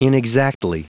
Prononciation du mot inexactly en anglais (fichier audio)
Prononciation du mot : inexactly